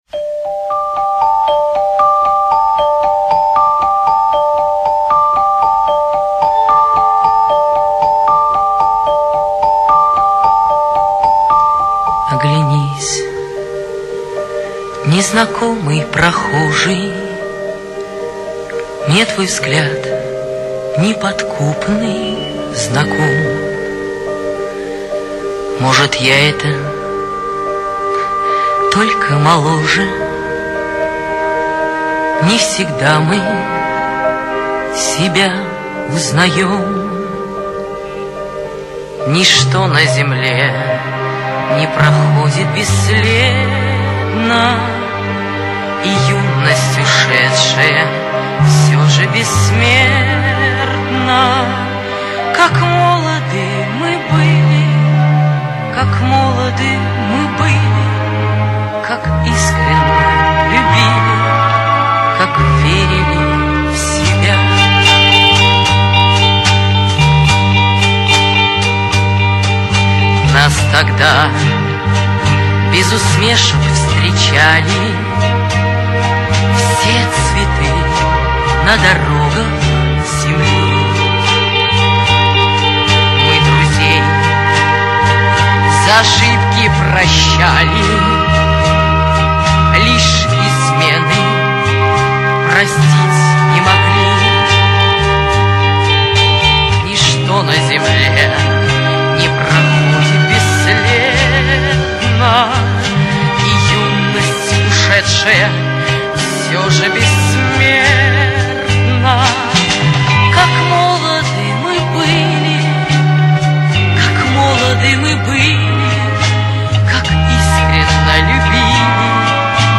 немножко выделен вокал